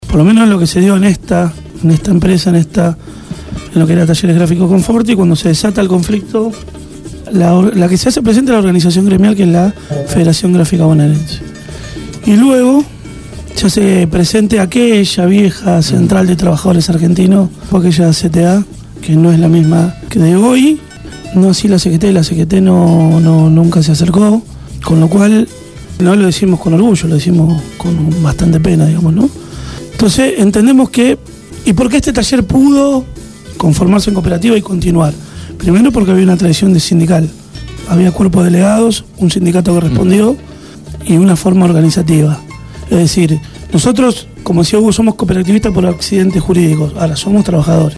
Entrevista
en los estudios de Radio Gráfica FM 89.3 con motivo del encuentro de trabajadores de empresas autogestionadas que se realizado el viernes 14 de octubre en la Cooperativa Gráfica Patricios.